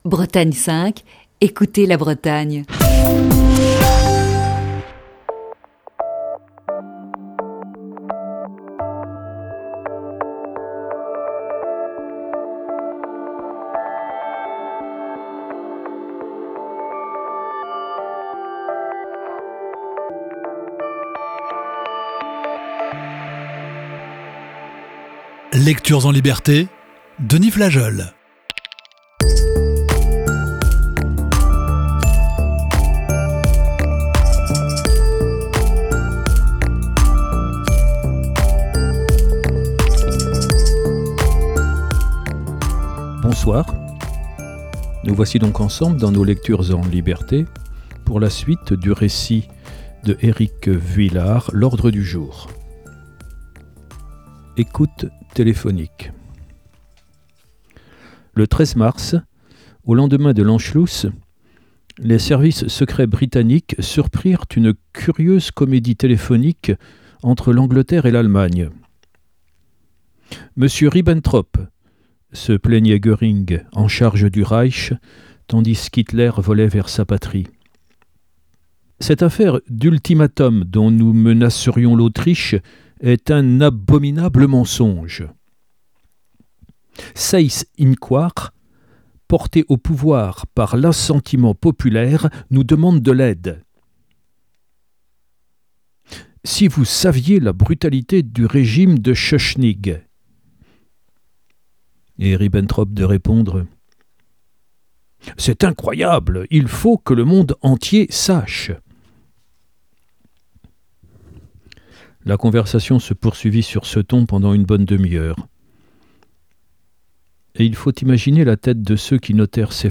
Voici ce soir la septième partie de ce récit.